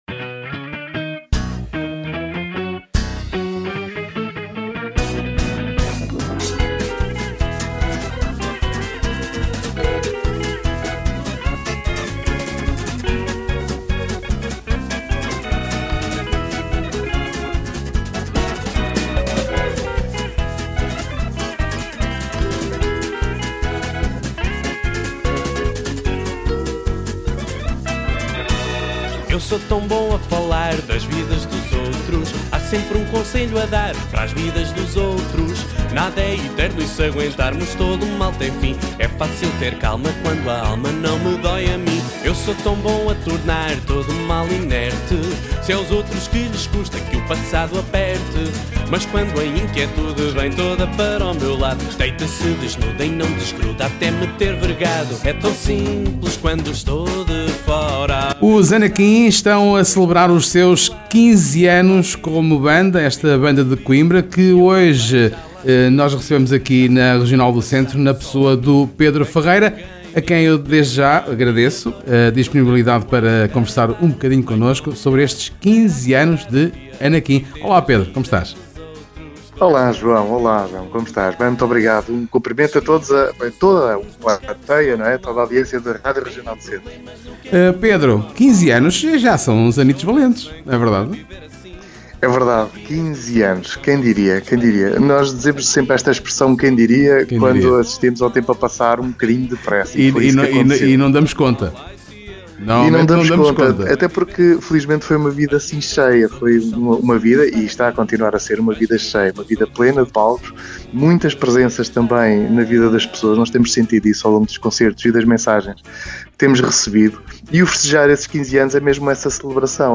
15 anos de Anaquim – a entrevista